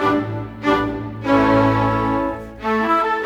Rock-Pop 06 Orchestra 03.wav